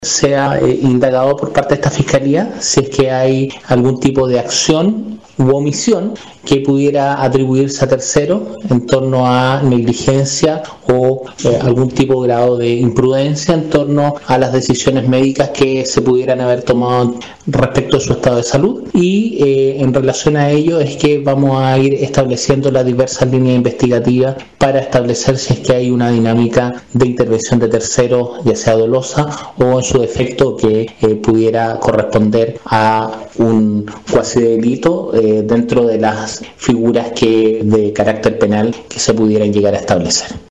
Si bien la causa de muerte de la menor de 10 años en Puerto Montt, señala que fue por Neumonía, tras la autopsia realizada en el Servicio Médico Legal,se realizó el test PCR, post mortem, el cual arrojó presencia de coronavirus en el cuerpo de la niña, lo que motivó al Ministerio Público dar curso a una investigación en conjunto con la Brigada de Homicidios de la PDI, como lo detalla el Fiscal Jefe de Puerto Montt, Marcelo Maldonado. El persecutor agregó que la línea de investigación busca establecer si hubo o no responsabilidad de terceras personas en la muerte de la niña, ya sea por acción u omisión, que pudiesen llevar a la Fiscalía a una persecución penal por cuasidelito de homicidio.